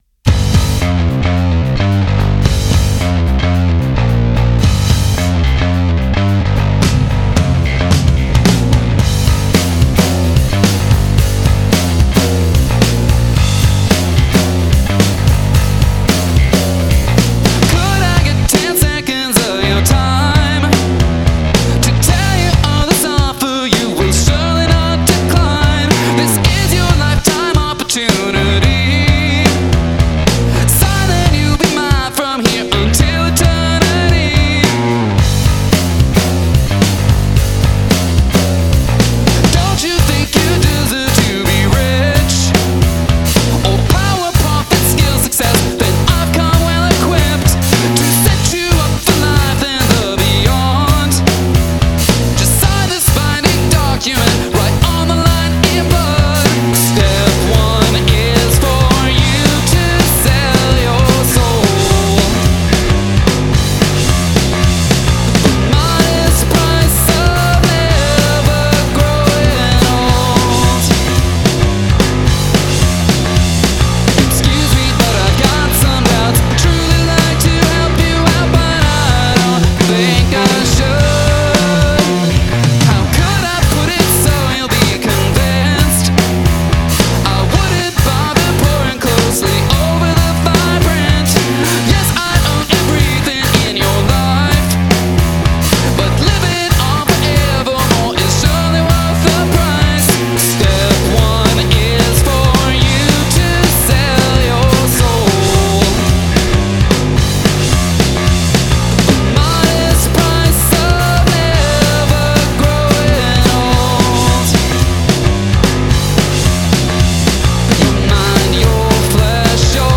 Music was solid, but honestly this round was strong.